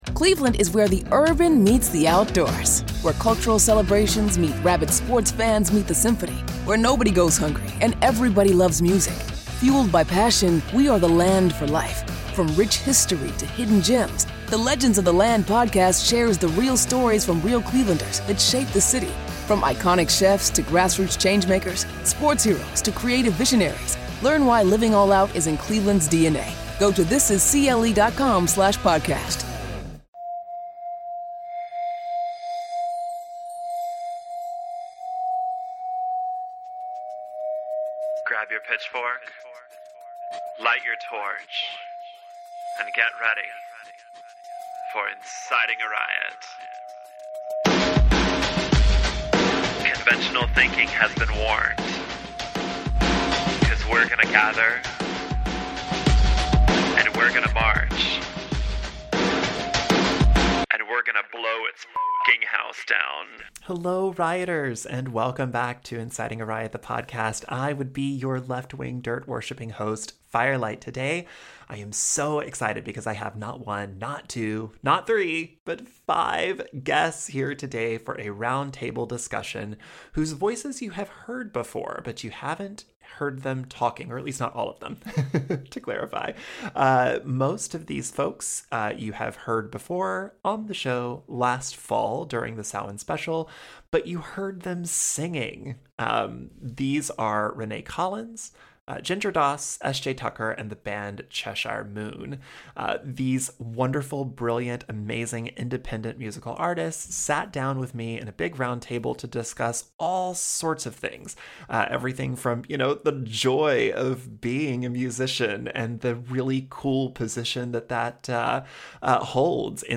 Episode 168 of Inciting A Riot is a roundtable with some familiar voices.